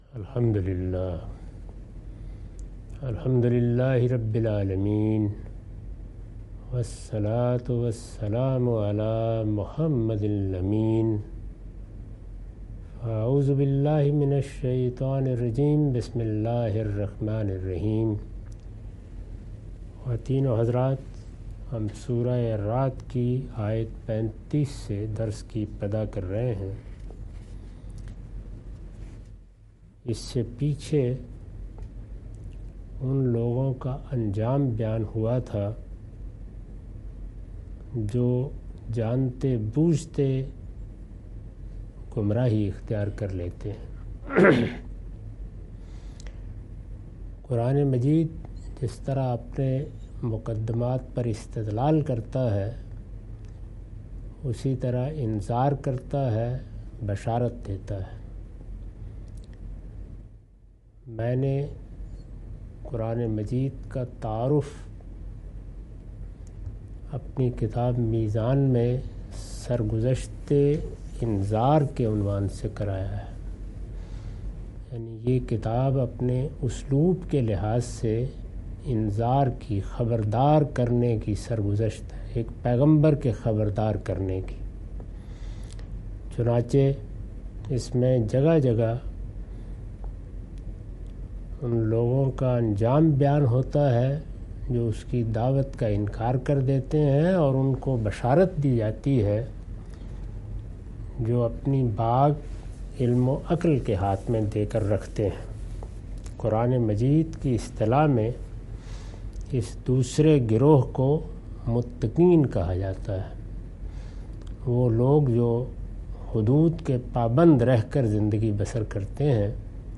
Surah Ar-Rad - A lecture of Tafseer-ul-Quran – Al-Bayan by Javed Ahmad Ghamidi. Commentary and explanation of verses 35-39.